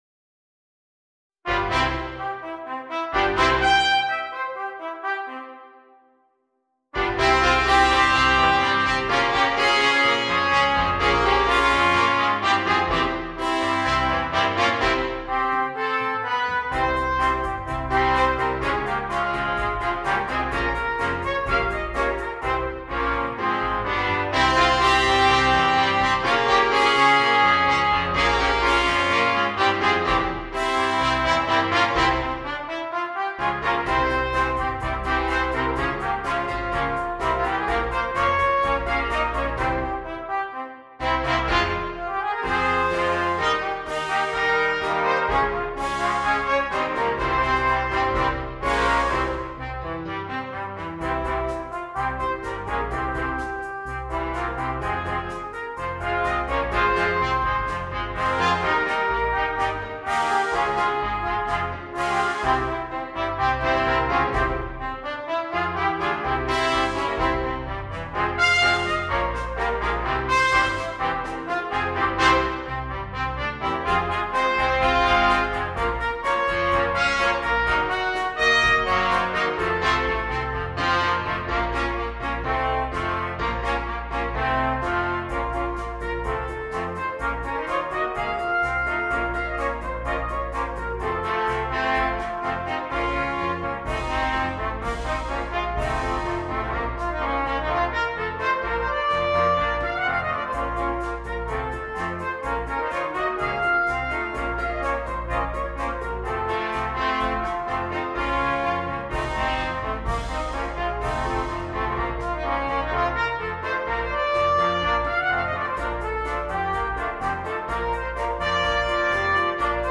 トランペット六重奏